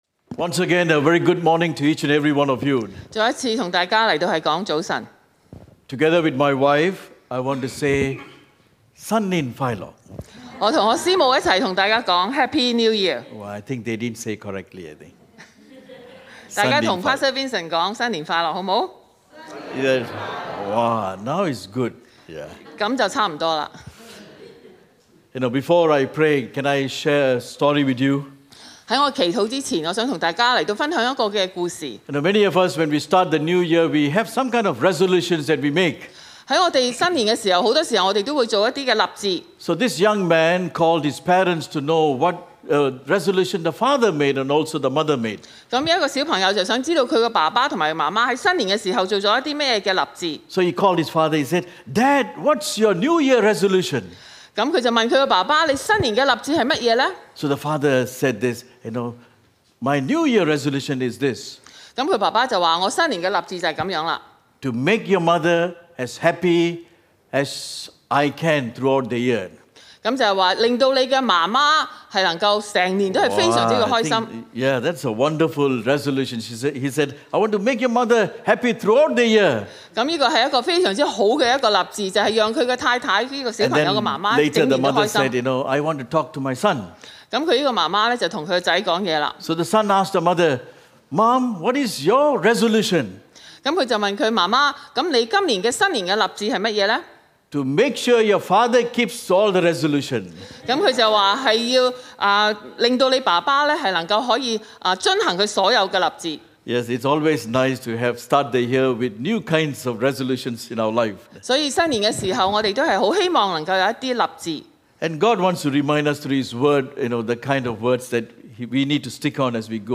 Sermons | New Covenant Alliance Church (NCAC) 基約宣道會 - Part 13